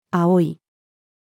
葵-female.mp3